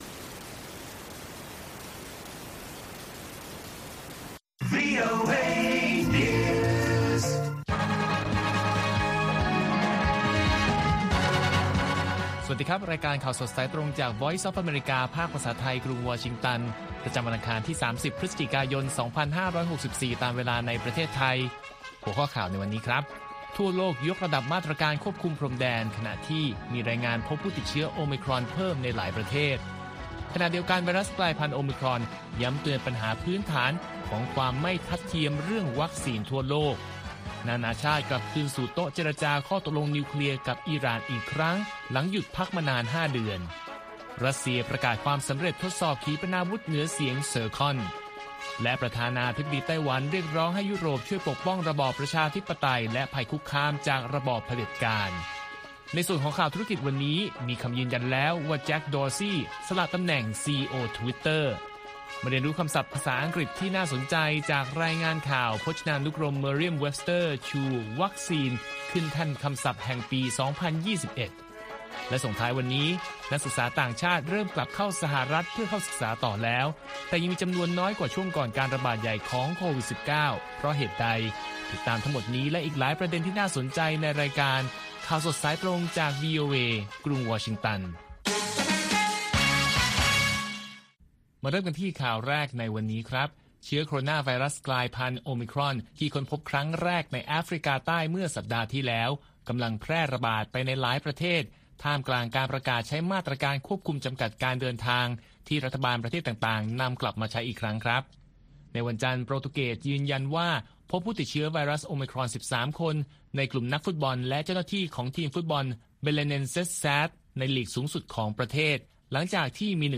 ข่าวสดสายตรงจากวีโอเอ ภาคภาษาไทย ประจำวันอังคารที่ 30 พฤศจิกายน 2564 ตามเวลาประเทศไทย